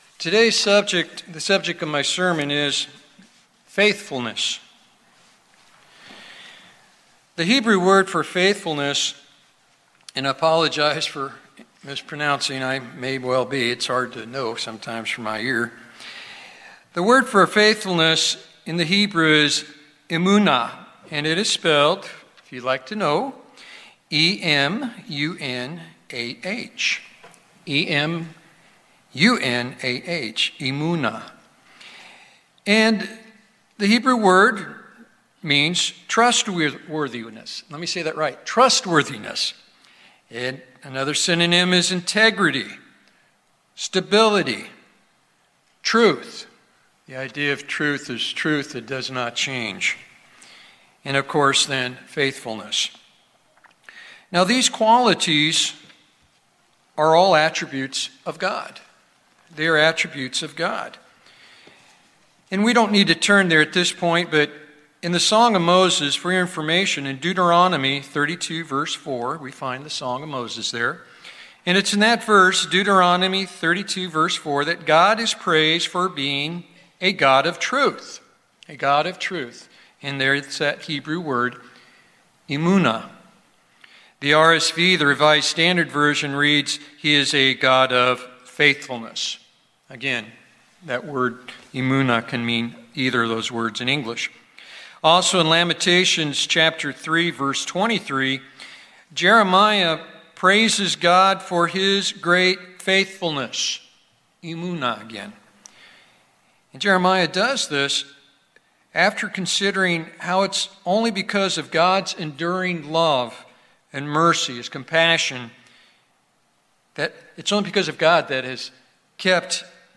Better Your Faithfulness, Better Your Life | United Church of God